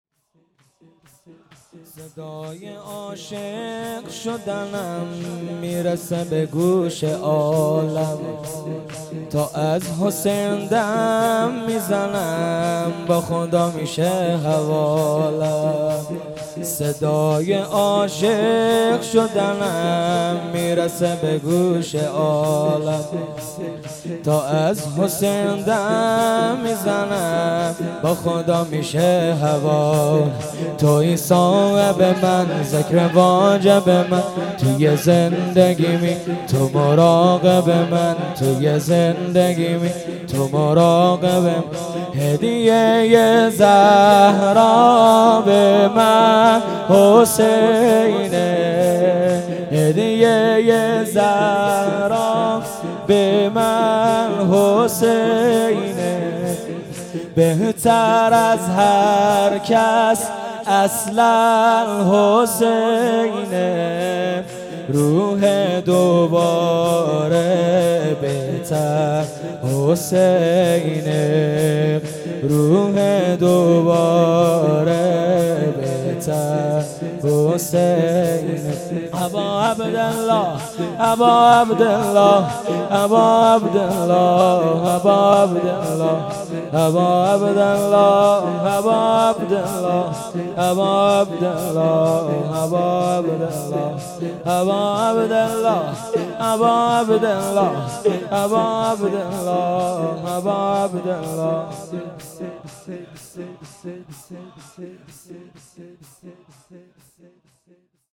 هیئت محبین انصار المهدی(عج)_محرم98
شب پنجم محرم 1398